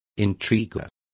Complete with pronunciation of the translation of intriguers.